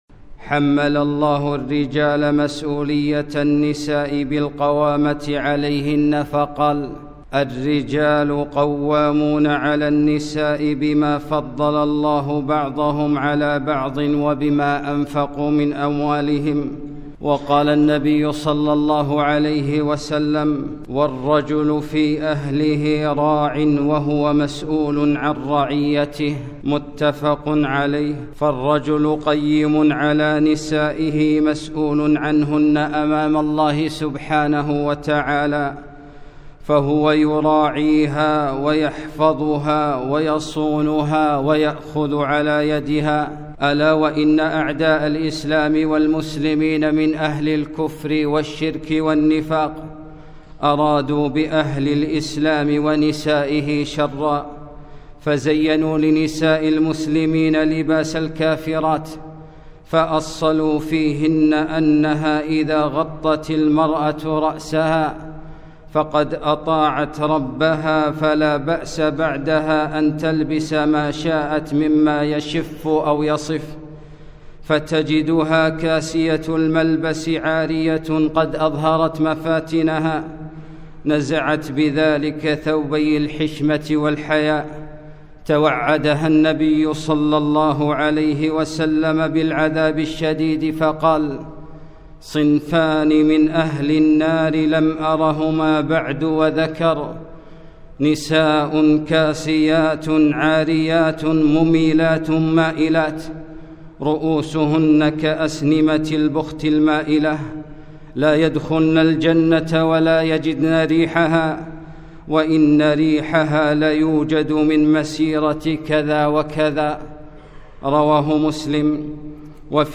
خطبة - كاسيات عاريات